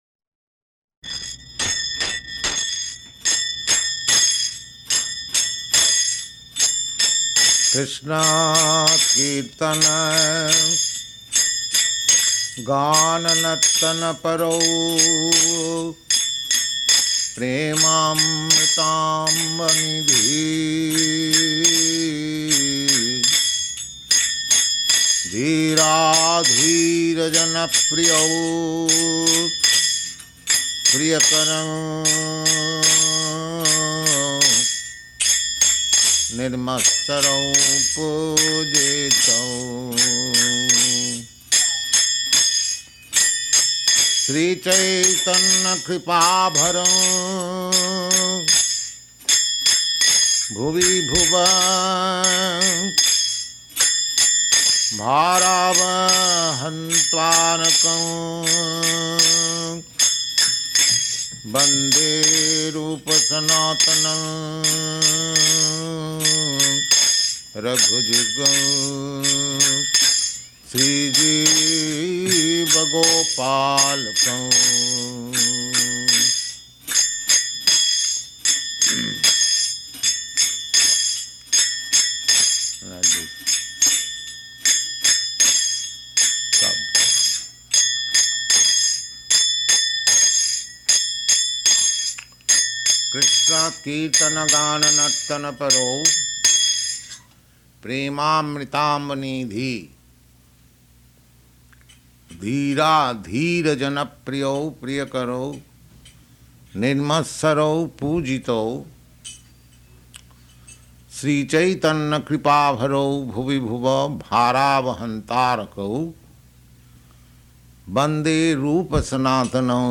Type: Lectures and Addresses
Location: Los Angeles